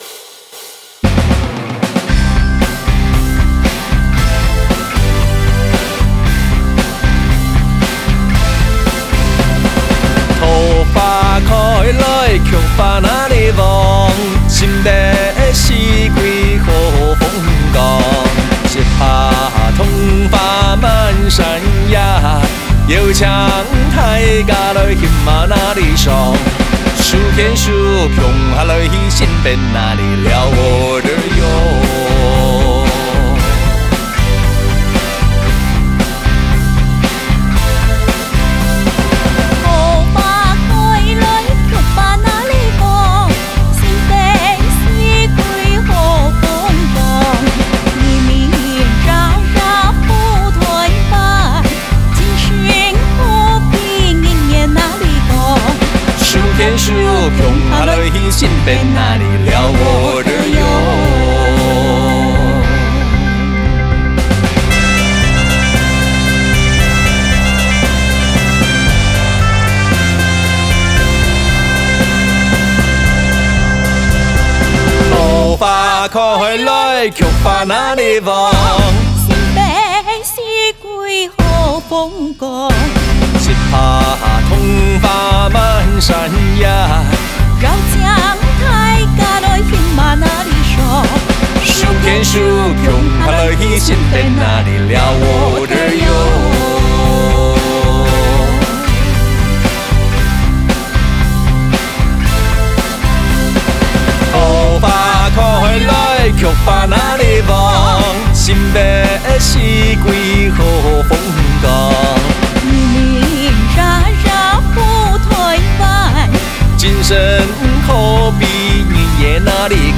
客家小調《桃花開》 | 新北市客家文化典藏資料庫